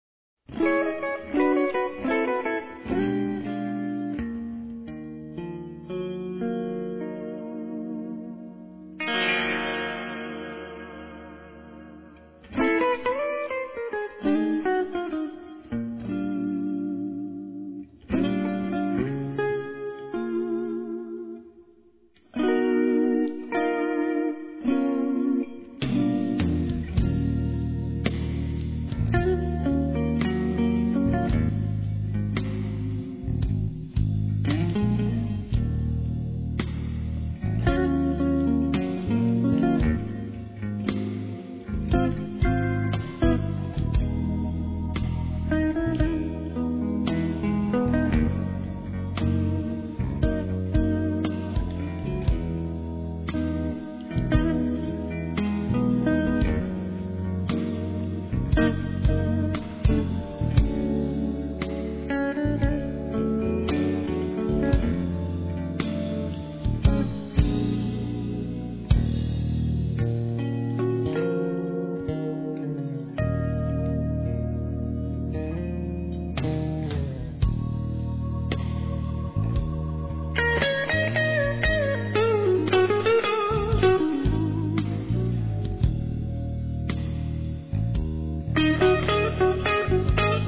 slow bluesy langoureusement jazzy